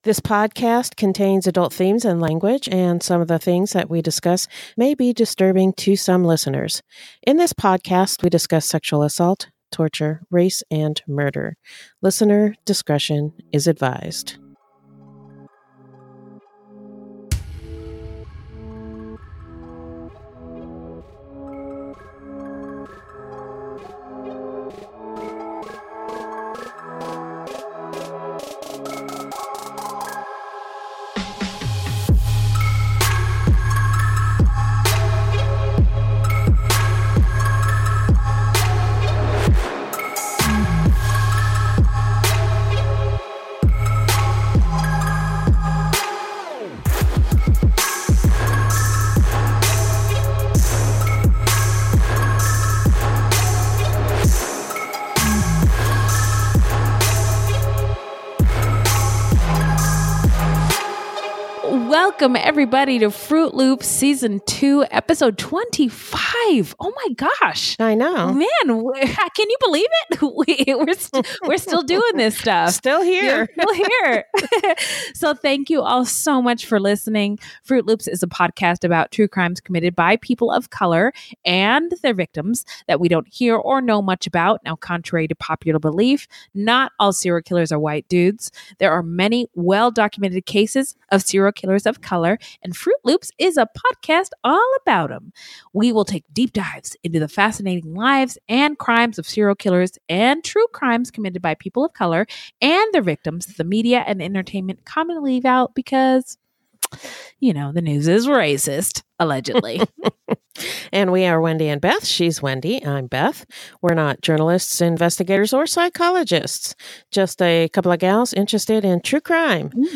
Despite multiple technical issues
a fit of hiccups and a laughter attack